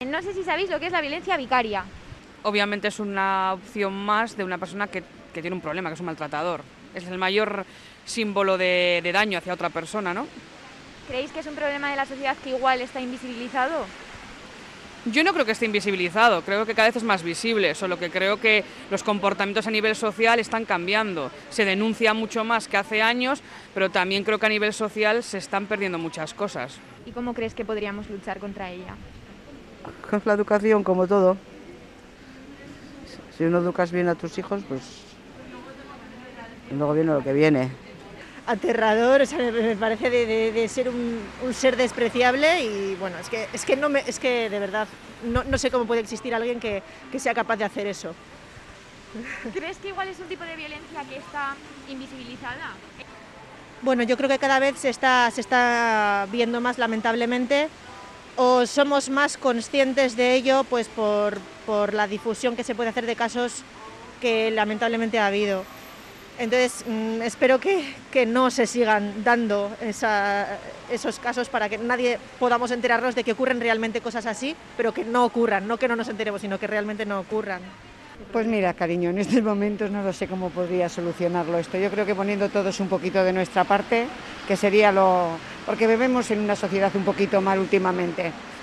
Hoy en Onda Vasca salimos a preguntar si la ciudadanía conoce este tipo de violencia de género